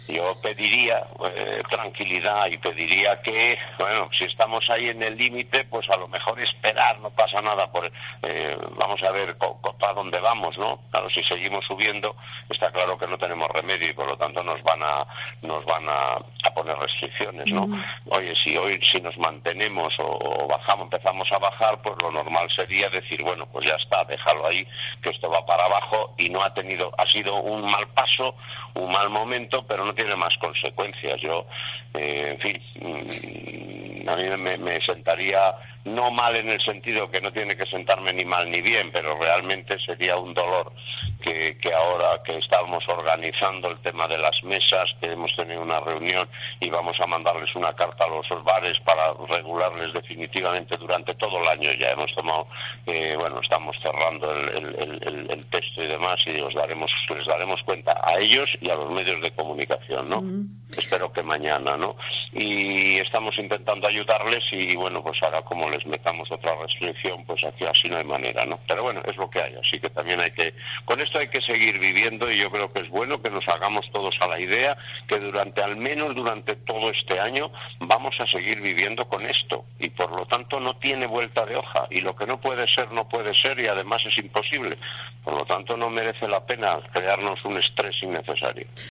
Declaraciones de Alfredo García sobre las restricciones que podrían afectar a la hostelería en O Barco